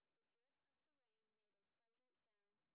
sp12_street_snr10.wav